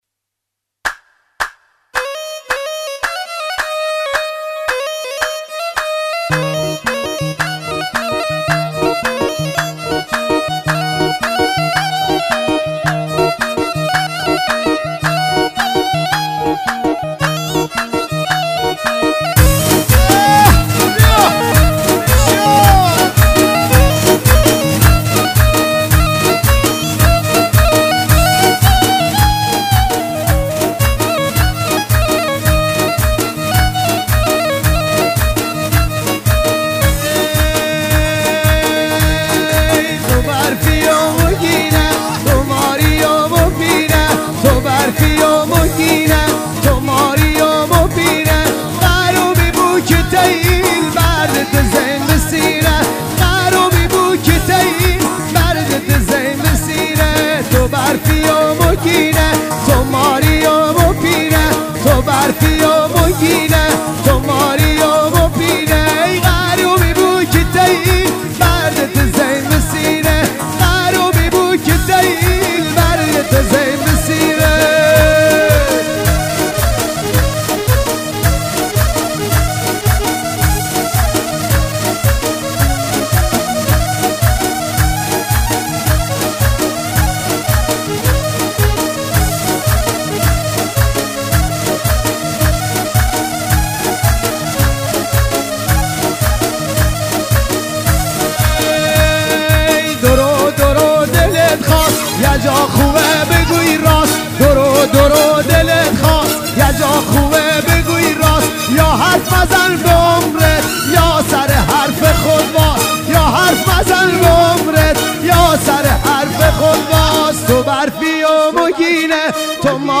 بختیاری